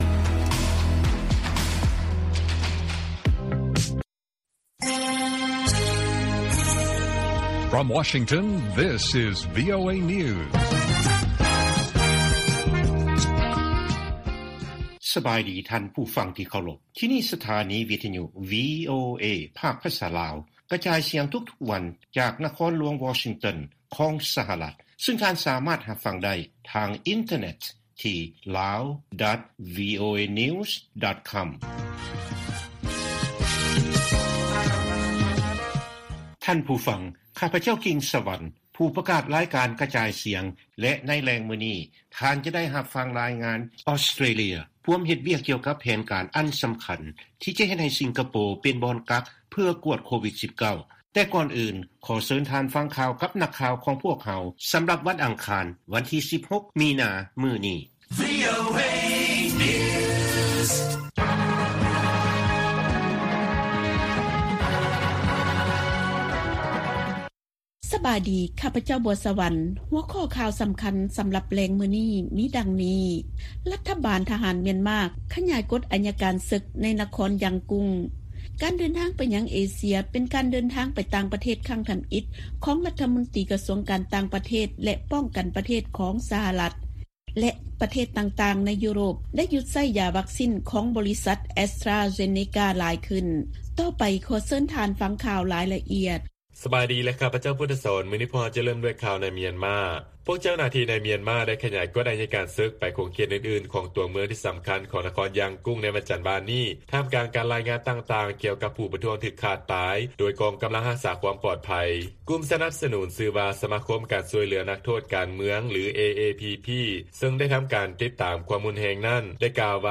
ວີໂອເອພາກພາສາລາວ ກະຈາຍສຽງທຸກໆວັນ. ຫົວຂໍ້ຂ່າວສໍາຄັນໃນມື້ນີ້ມີ: 1) ລັດຖະບານທະຫານ ມຽນມາ ຂະຫຍາຍ ກົດໄອຍະການເສິກ ໃນນະຄອນ ຢ່າງກຸ້ງ . 2) ພວກນັກຊ່ຽວຊານ ຕັ້ງຄໍາຖາມຕໍ່ການງົດ ການສັກຢາວັກຊີແອສຕຣາເຊນເນກາໄວ້. 3) ທຳນຽບຂາວ ເລີ້ມການເດີນທາງ ໄປເຜີຍແຜ່ 'ການຊ່ວຍເຫຼືອມາຮອດແລ້ວ' ແລະຂ່າວສໍາຄັນອື່ນໆອີກ.